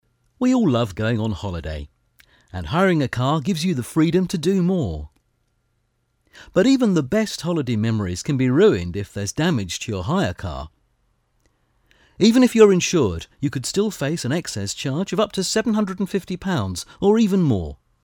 British English voiceover, mature voice, caring, authoritative.
Sprechprobe: Werbung (Muttersprache):
British English voiceover for commercials, documentaries, corporate videos and e-learning.